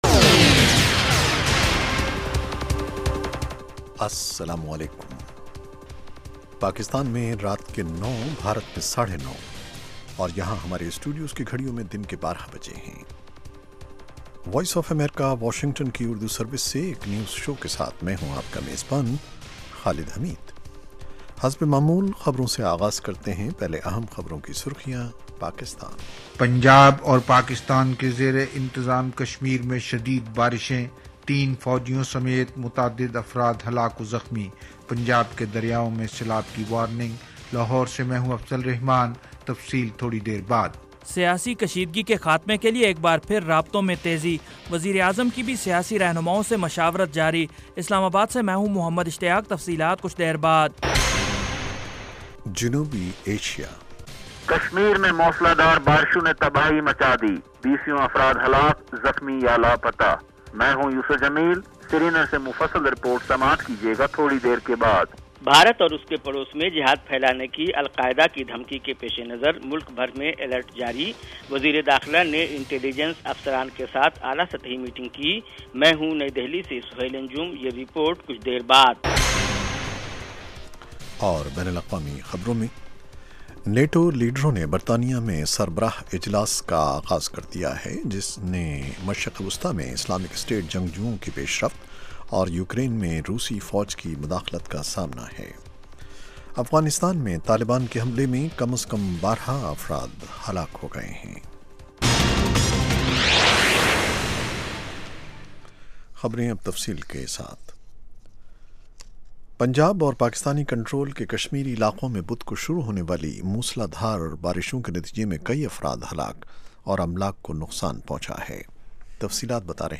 اس کے علاوہ انٹرویو، صحت، ادب و فن، کھیل، سائنس اور ٹیکنالوجی اور دوسرے موضوعات کا احاطہ۔